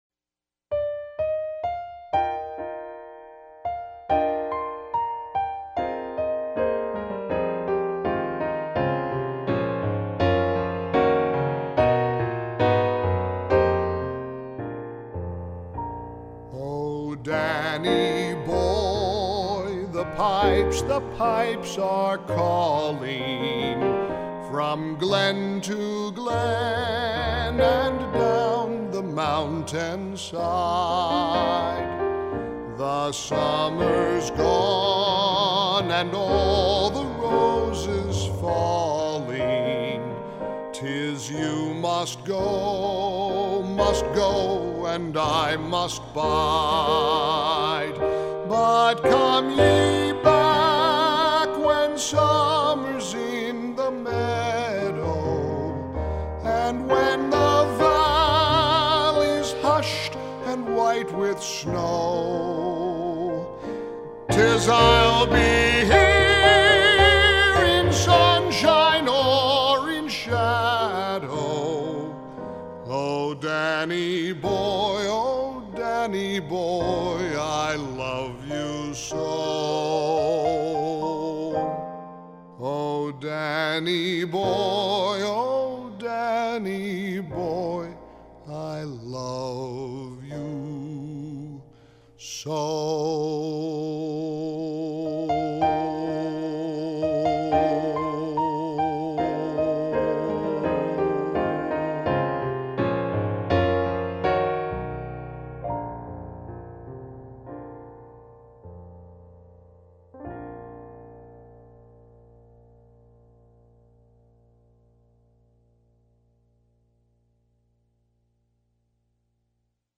In what has become an annual tradition, Mike sings “Danny Boy.”
Hear Mike Gallagher sing, Danny Boy; it’s magically delicious.
MG_Sings_Danny_Boy.mp3